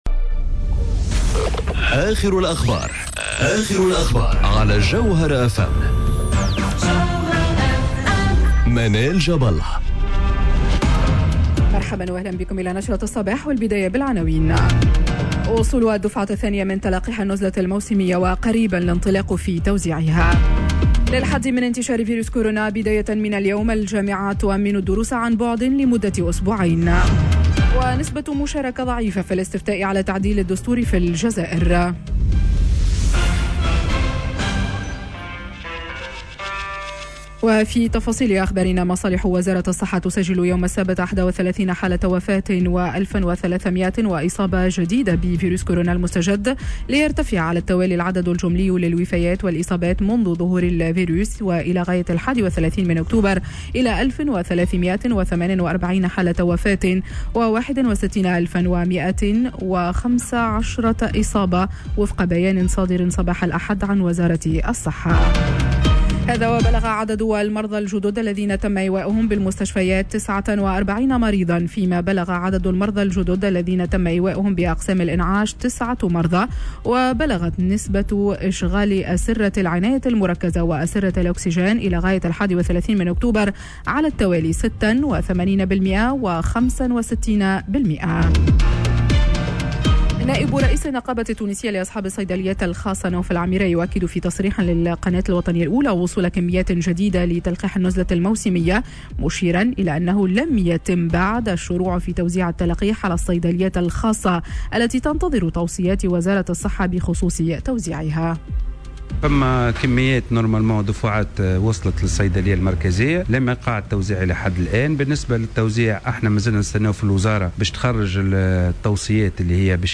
نشرة أخبار السابعة صباحا ليوم الإثنين 02 نوفمبر 2020